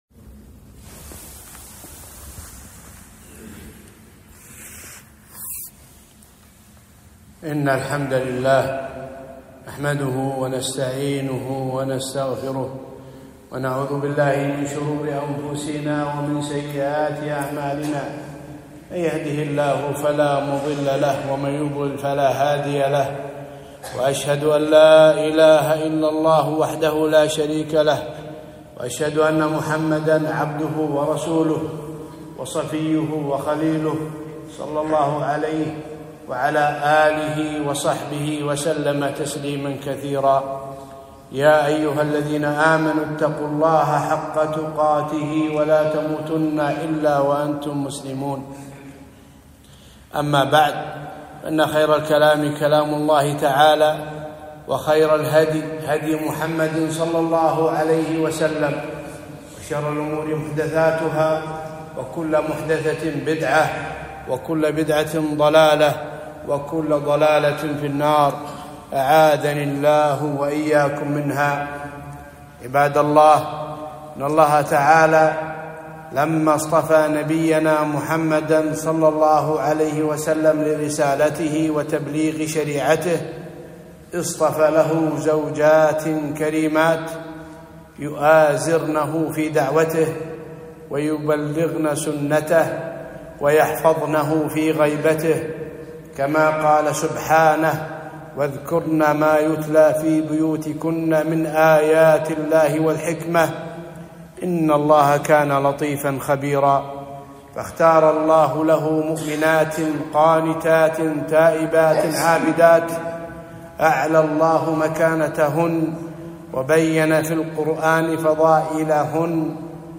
خطبة- أزواج النبي أمهات المؤمنين